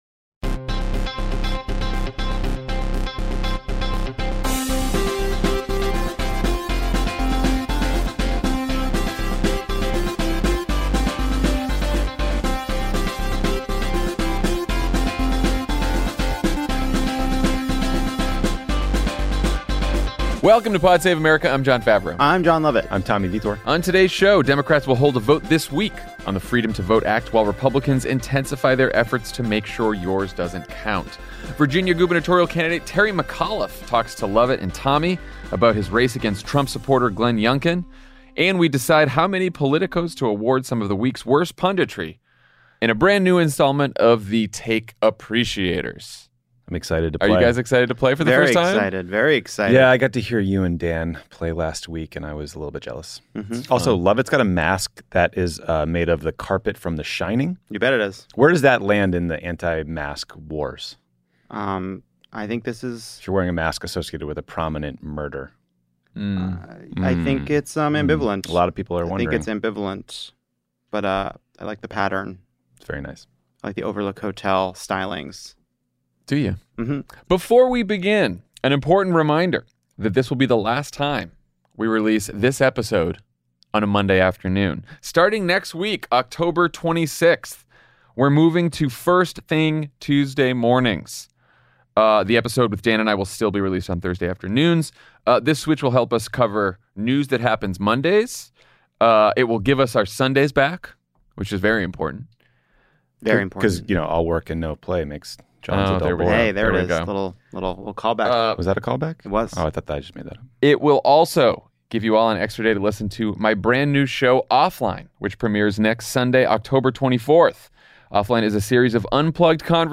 Democrats schedule a vote on a new voting rights bill while Republicans run more Big Lie candidates, Virginia gubernatorial candidate Terry McAuliffe talks to Jon Lovett and Tommy Vietor about his race against Trump supporter Glenn Youngkin, and the week’s worst punditry gets its due in a new installment of The Take Appreciators.